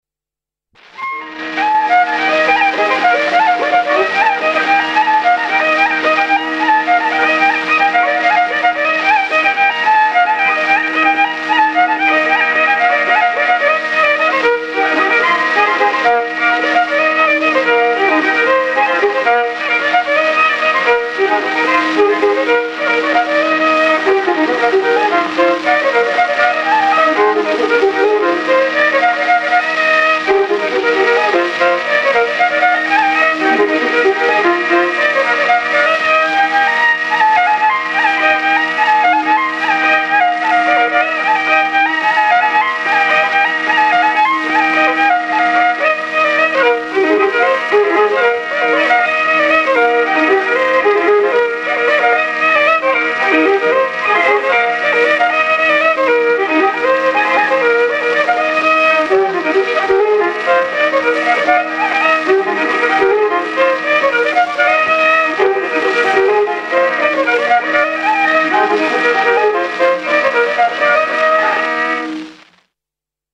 I viiul
burdoonsaade ��������